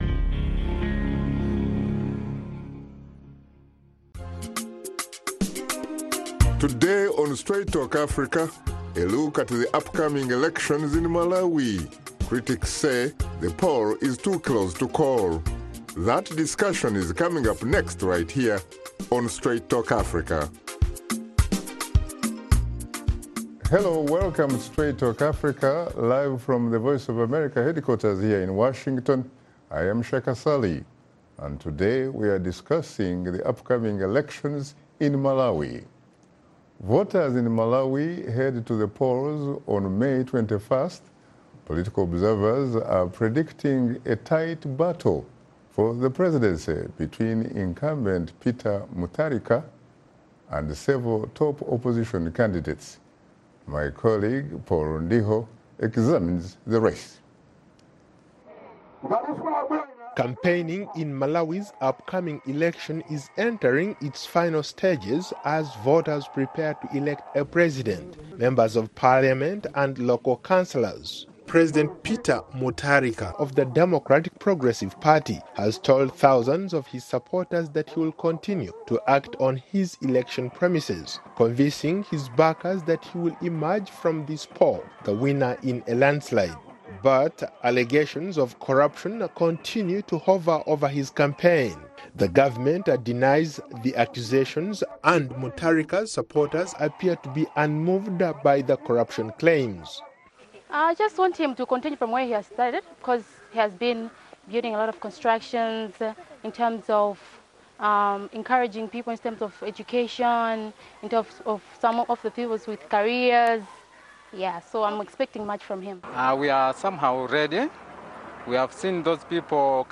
In this episode of Straight Talk Africa host Shaka Ssali discusses the upcoming elections in Malawi on May 21st.